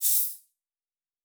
Air Hiss 2_01.wav